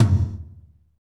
Index of /90_sSampleCDs/Northstar - Drumscapes Roland/DRM_Techno Rock/TOM_F_T Toms x
TOM F T L07R.wav